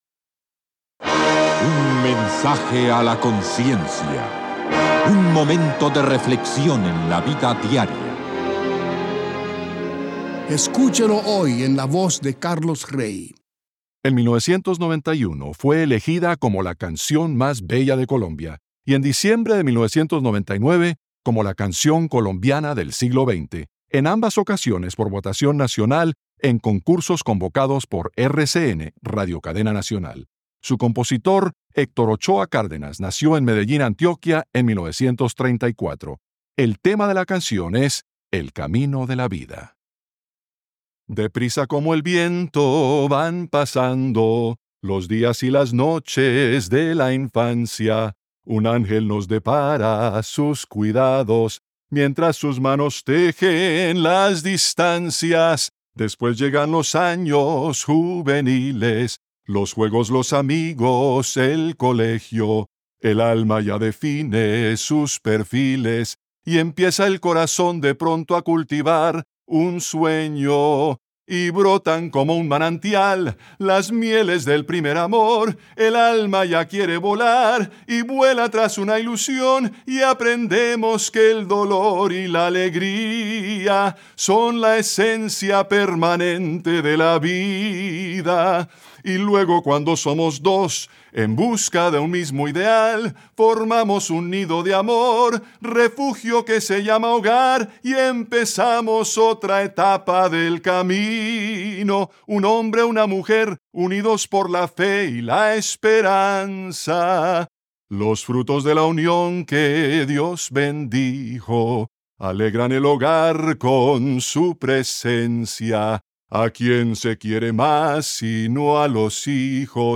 Canción cantada